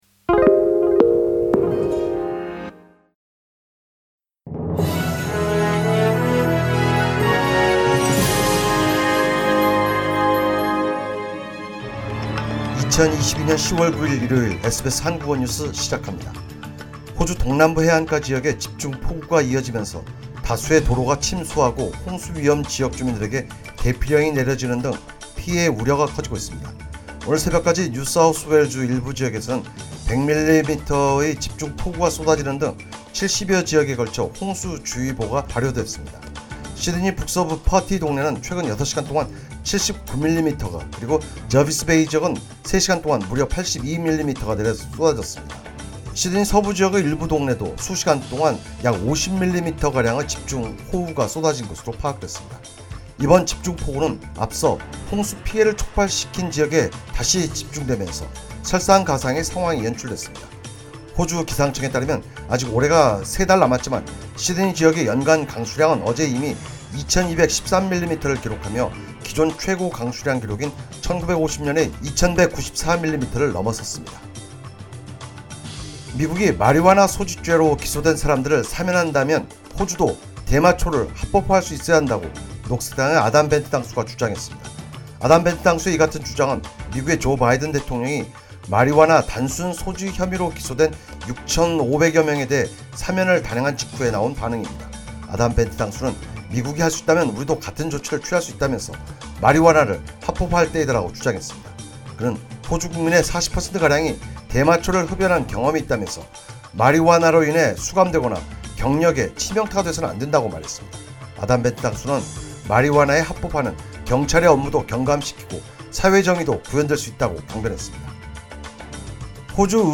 SBS 한국어 뉴스: 2022년 10월 9일 일요일